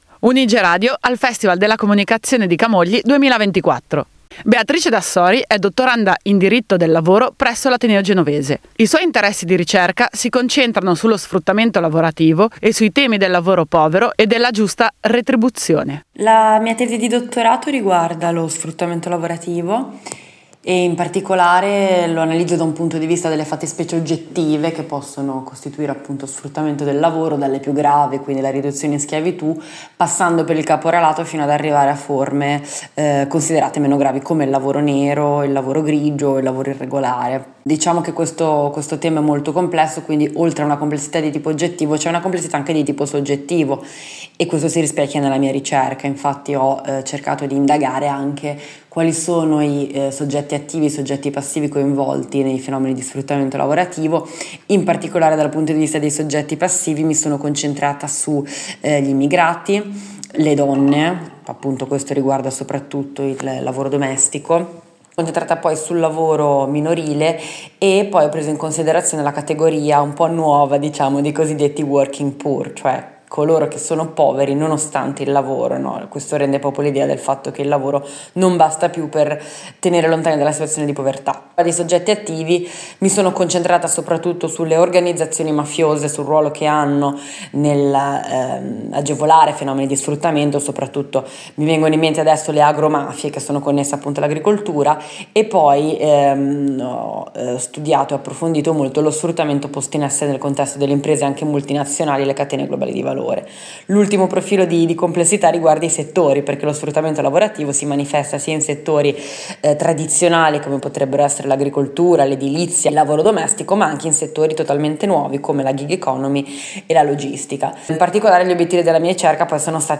Intervista e montaggio